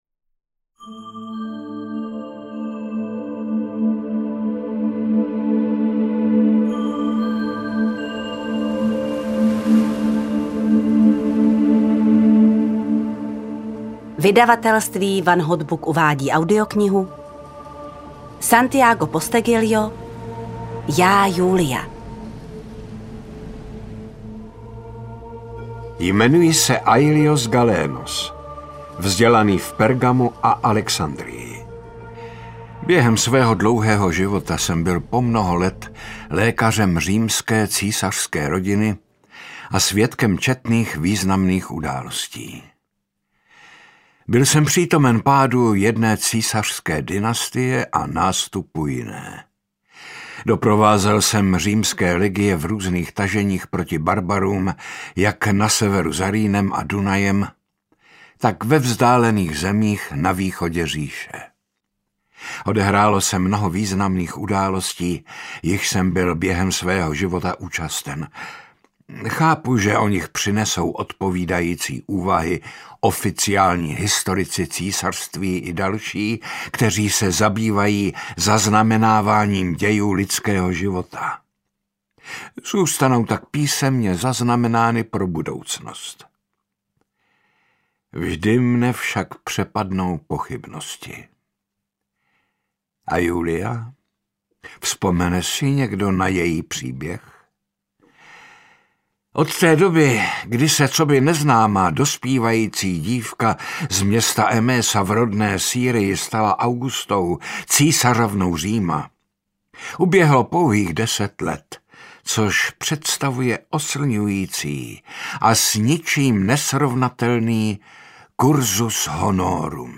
Já, Iulia audiokniha
Ukázka z knihy